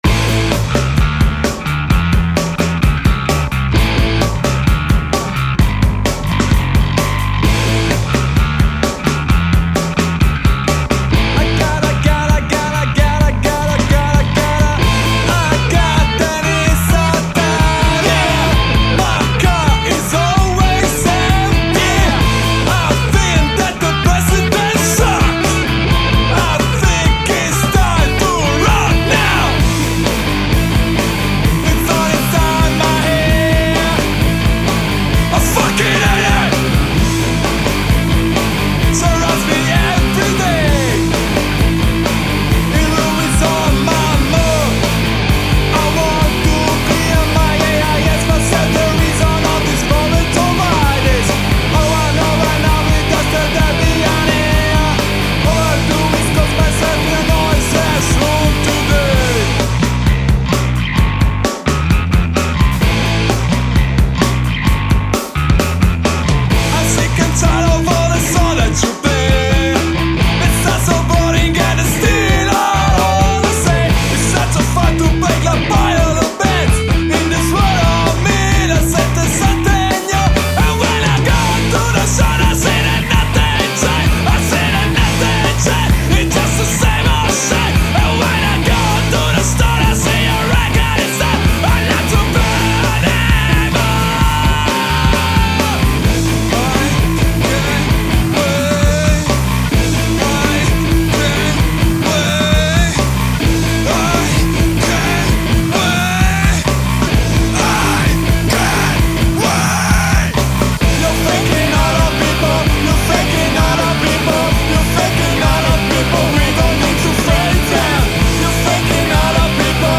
Genere: punk / rock /